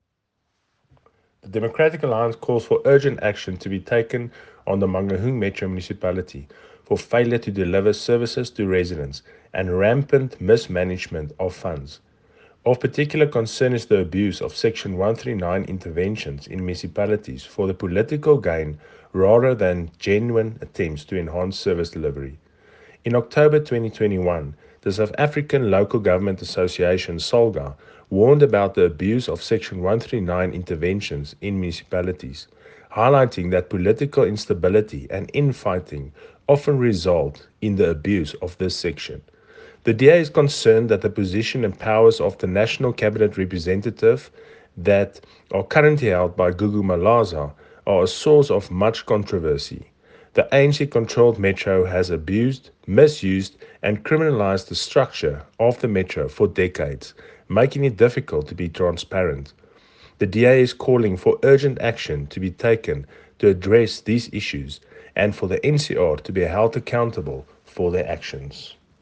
Afrikaans soundbites by Cllr Johan Pretorius and